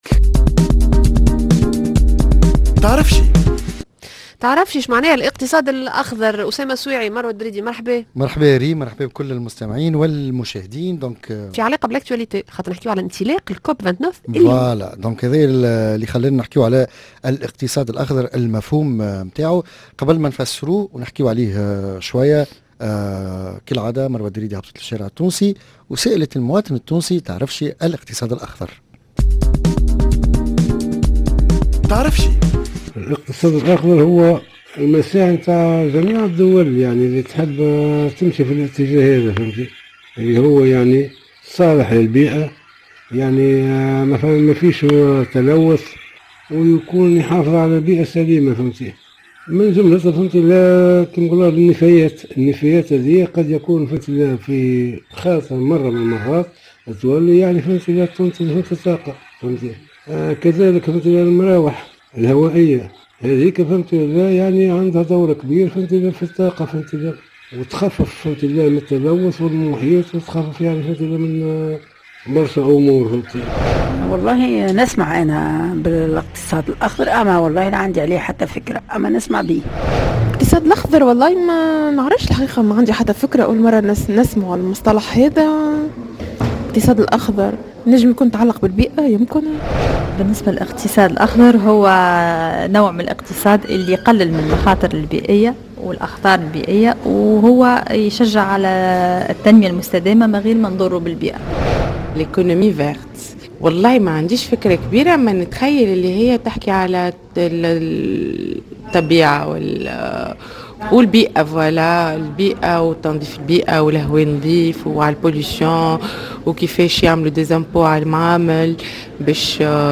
Micro trottoir